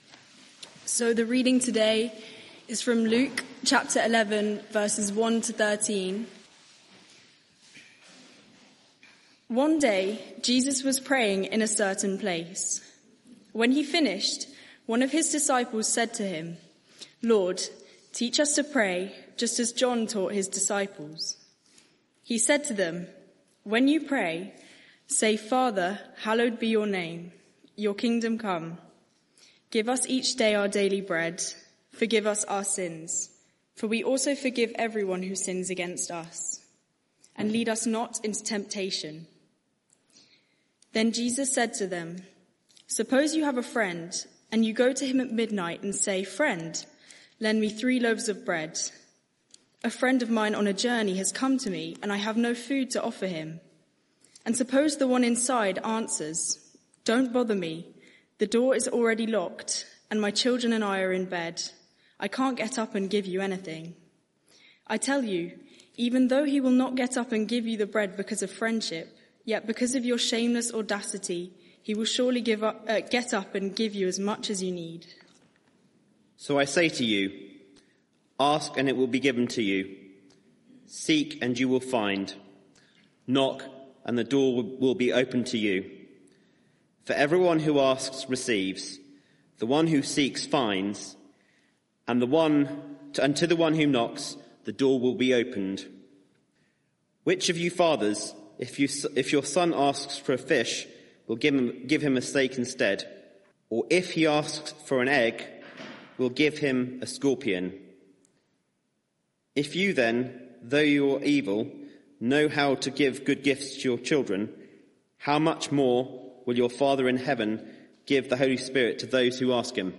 Media for 6:30pm Service on Sun 01st Jun 2025 18:30 Speaker
Sermon (audio) Search the media library There are recordings here going back several years.